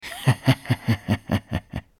B_RIRE.mp3